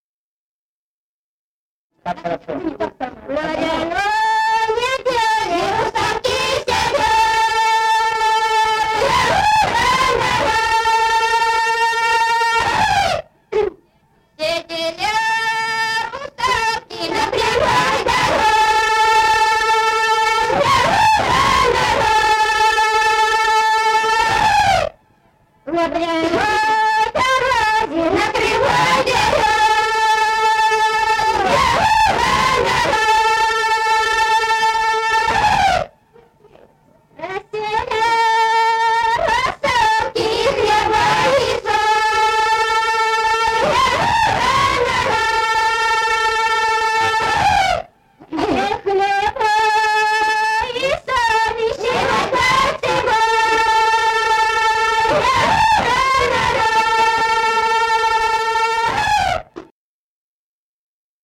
Народные песни Стародубского района «На гряной неделе», гряная.
с. Курковичи.